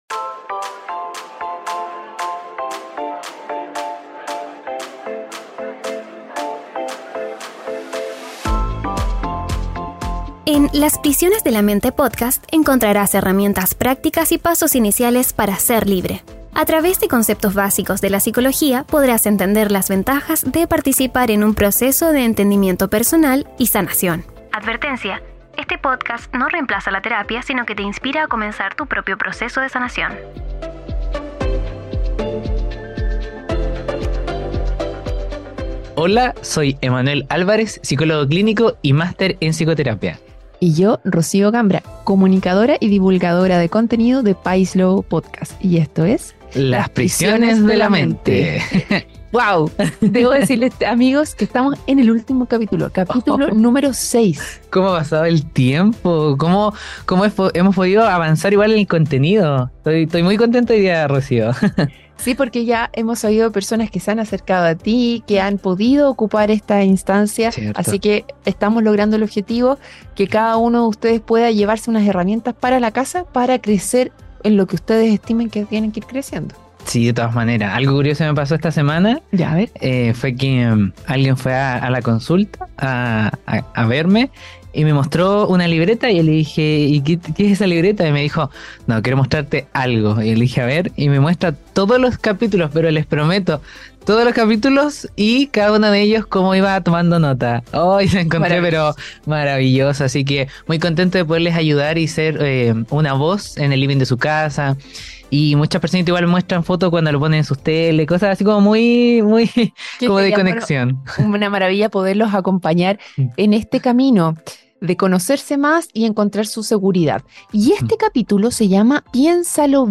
El episodio también recogió testimonios en primera persona sobre la relevancia que puede tener la terapia en momentos complejos de la vida.